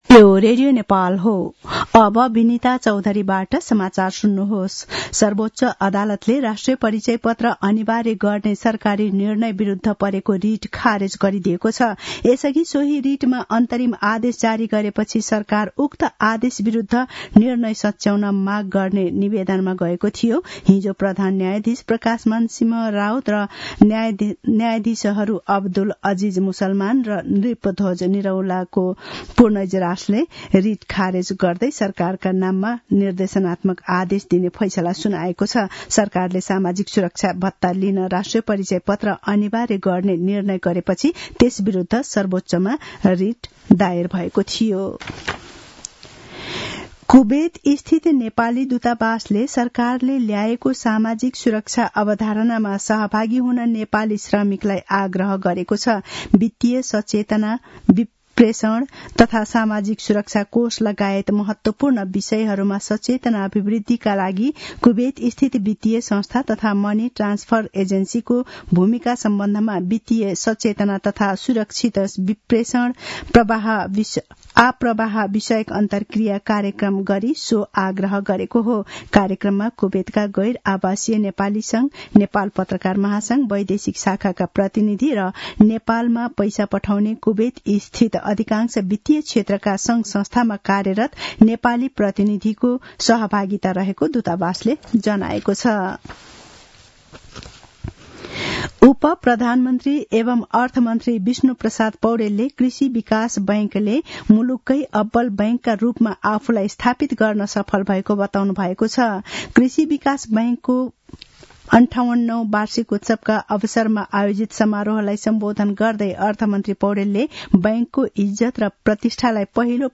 दिउँसो १ बजेको नेपाली समाचार : ८ माघ , २०८१